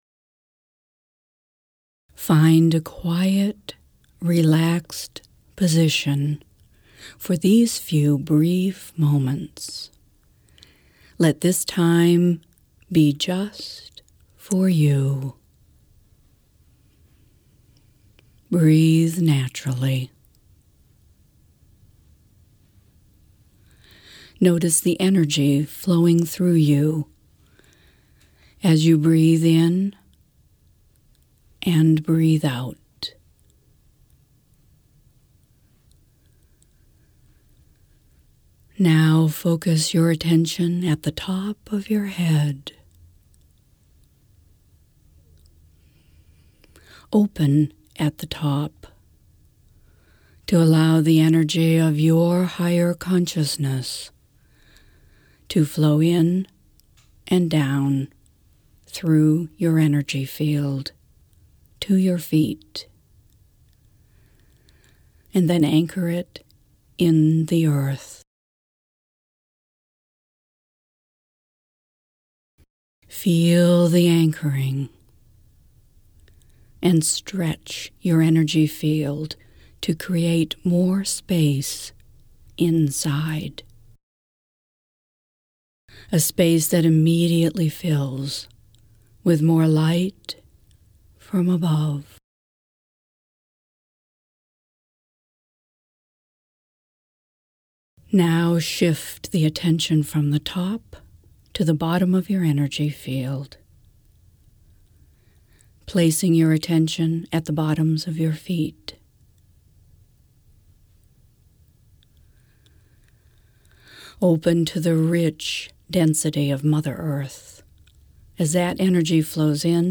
My voice alone without music
focused-energy-work-brief-no-music.mp3